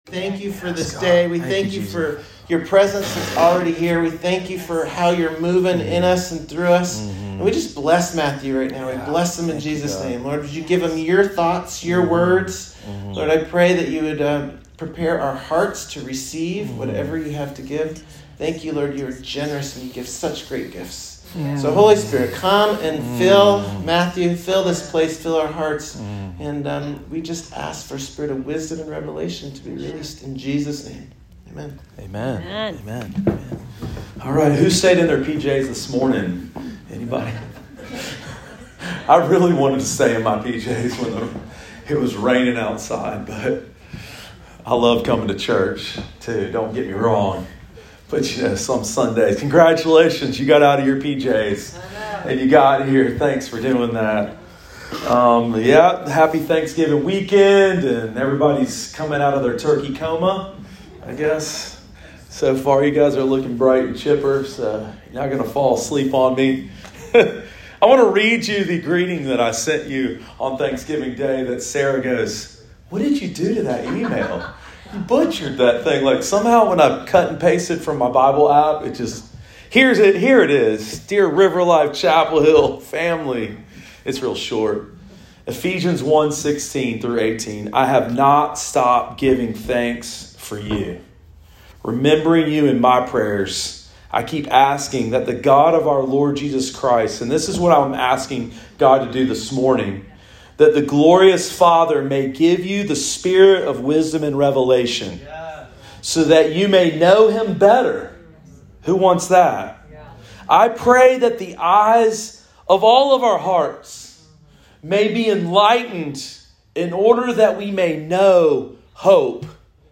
Sermon of the Week: 11/27/22 – RiverLife Fellowship Church